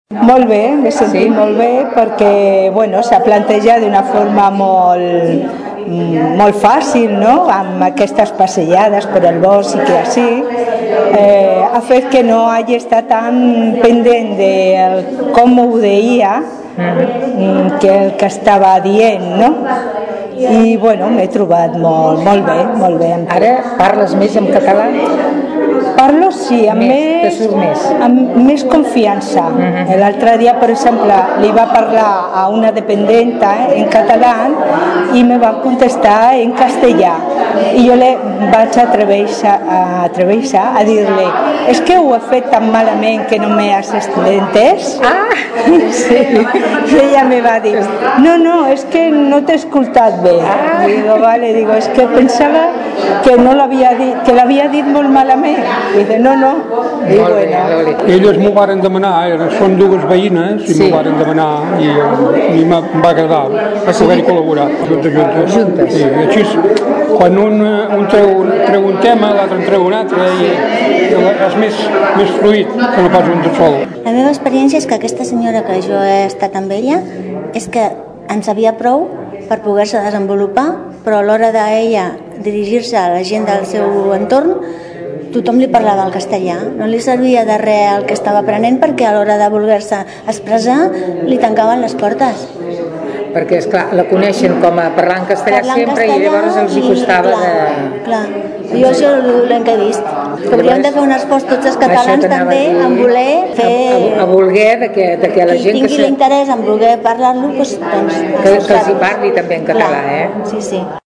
En declaracions a Ràdio Tordera, escoltem l’experiència d’algunes de les persones que han participat en aquest primer curs del Voluntariat per la Llengua a Tordera.